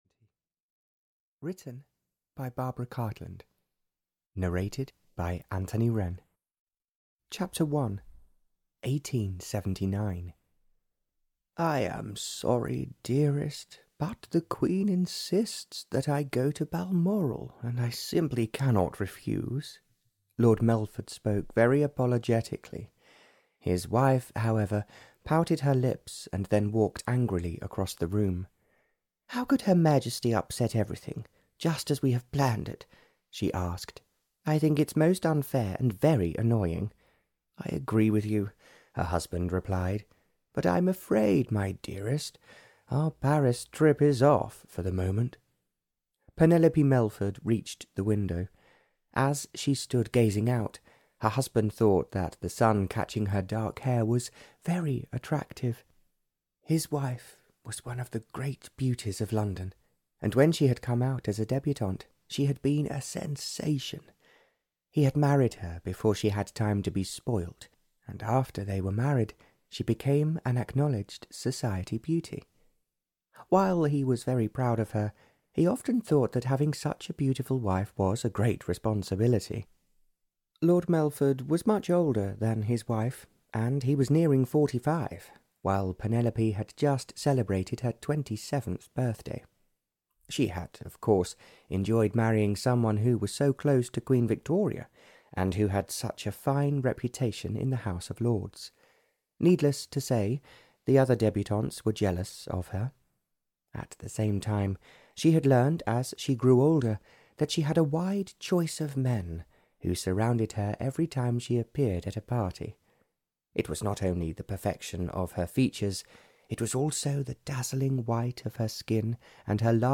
Love for Eternity (EN) audiokniha
Ukázka z knihy